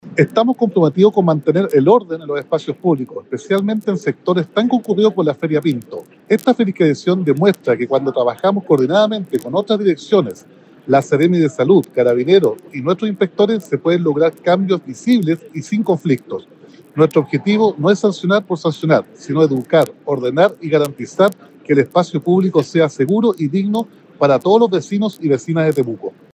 Roberto-Neira-alcalde-de-Temuco-2.mp3